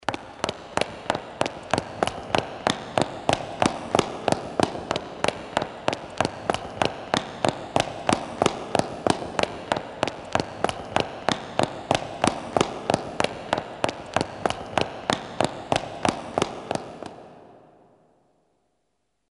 急促的高跟鞋在空旷混响强的环境1.mp3
通用动作/01人物/01移动状态/高跟鞋/急促的高跟鞋在空旷混响强的环境1.mp3
• 声道 立體聲 (2ch)